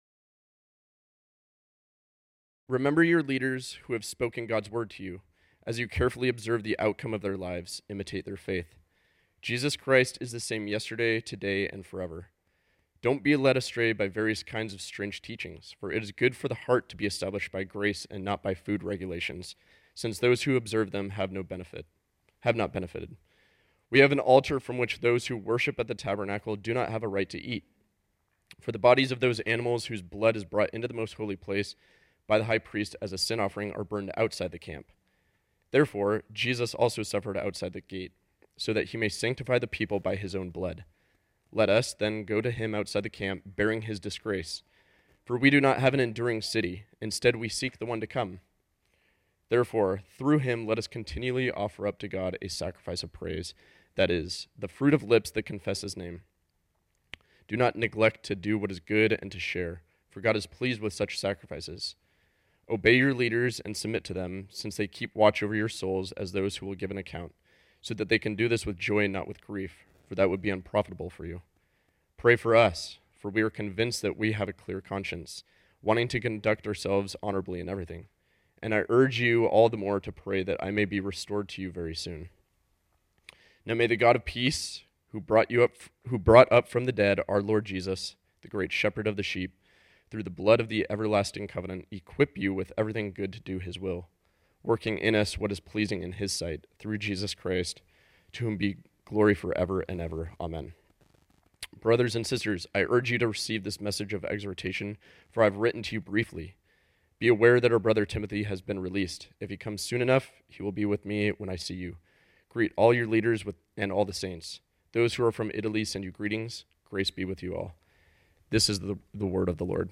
This sermon was originally preached on Sunday, March 19, 2023.